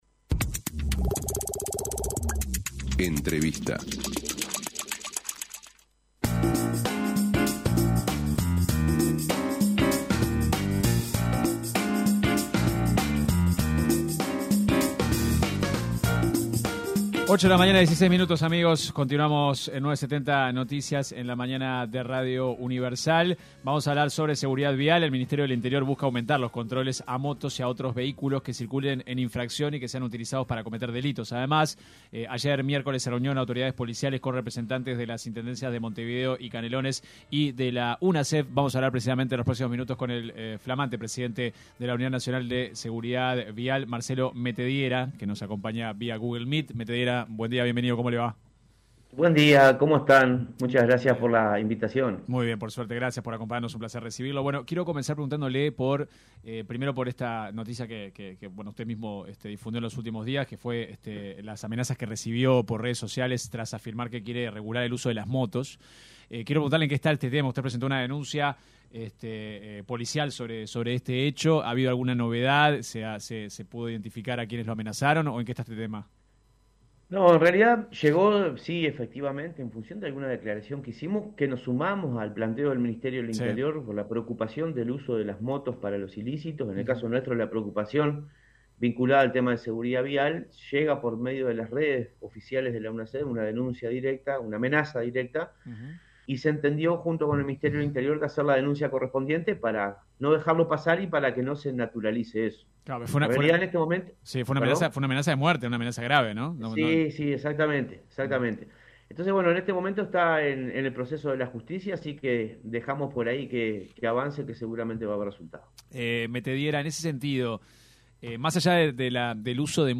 El director de la Unasev, y exintendente de Canelones, Marcelo Metediera, se refirió en diálogo con 970 Noticias, a los “ajustes” que se deben hacer para llegar a la licencia de conducir por puntos.